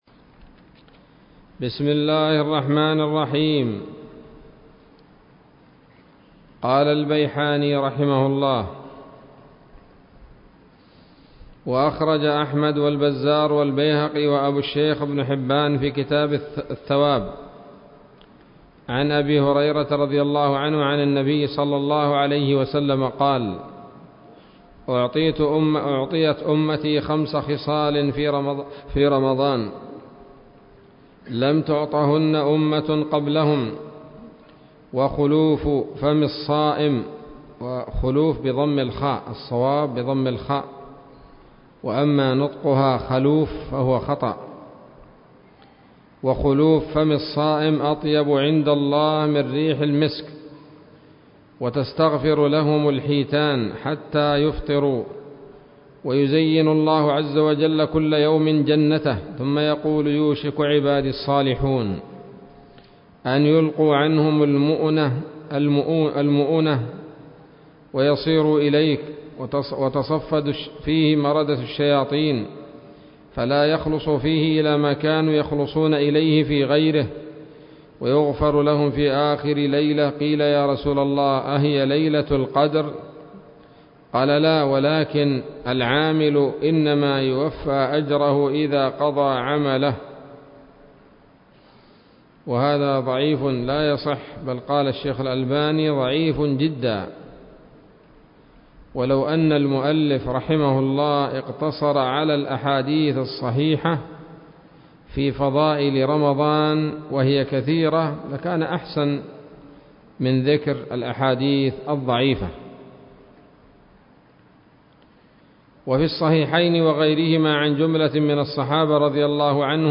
الدرس السادس من تحفة رمضان للعلامة البيحاني [1443هـ]